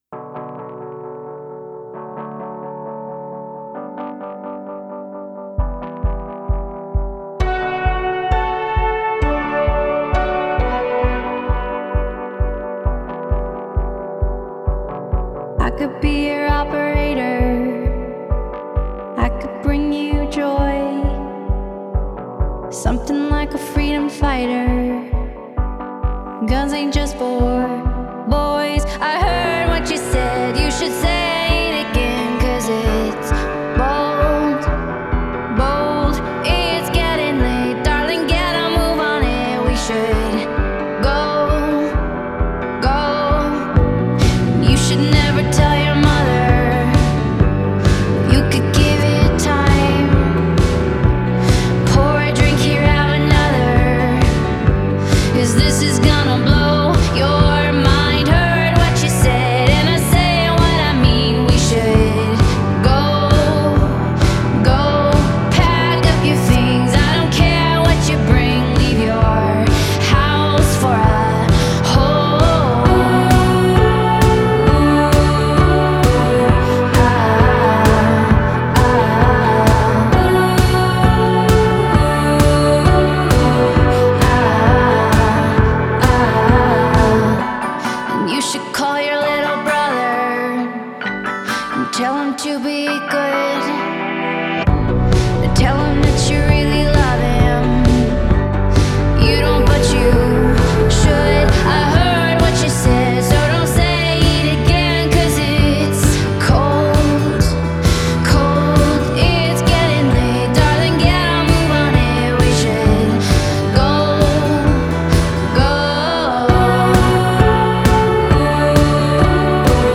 Genre: Indie Pop, Pop Folk, Singer-Songwriter